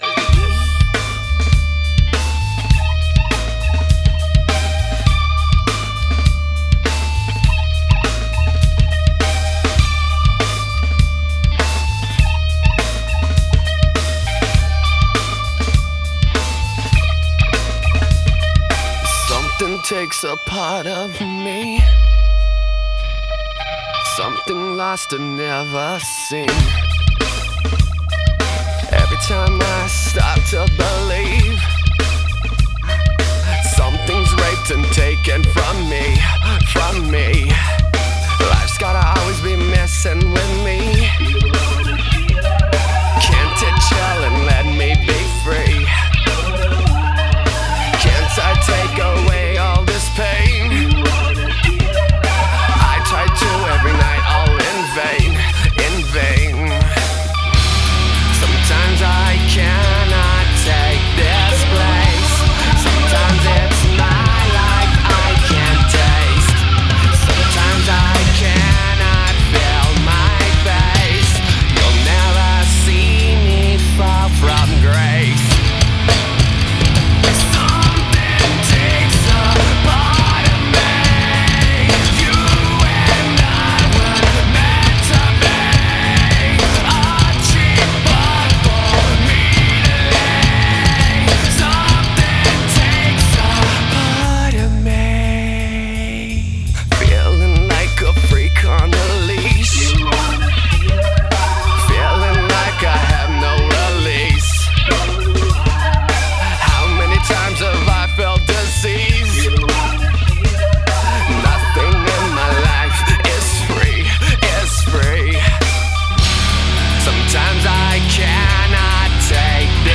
2025-01-03 17:31:02 Gênero: Rock Views